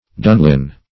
Dunlin \Dun"lin\, n. [Prob. of Celtic origin; cf. Gael. dun hill